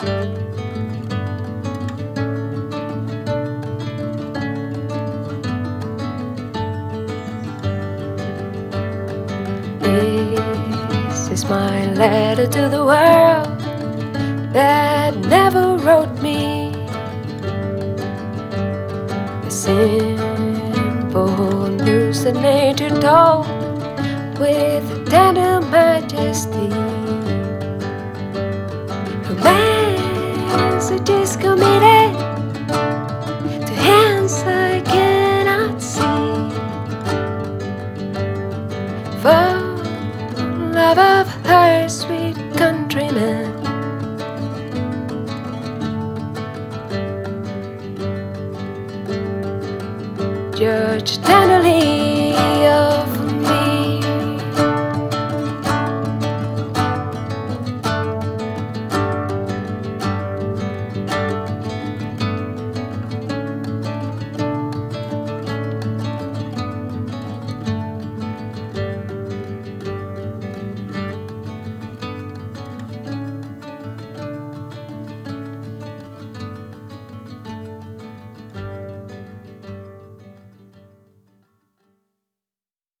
Voz y guitarra acústica
Guitarra acústica
Grabado en Cholula, México, 2022.